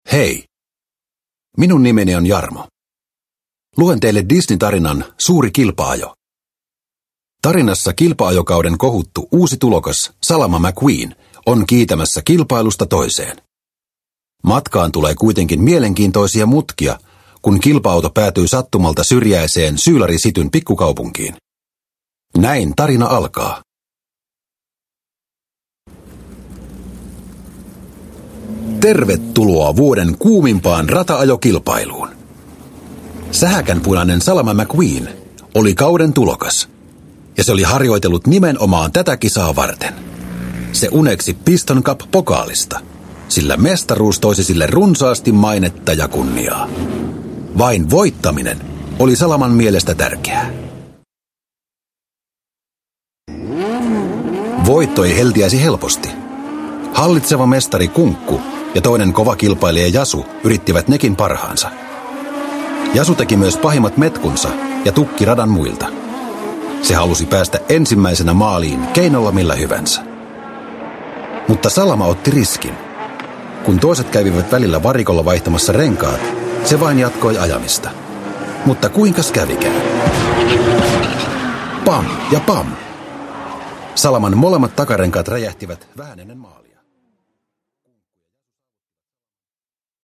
Pixar Autot. Suuri kilpa-ajo – Ljudbok – Laddas ner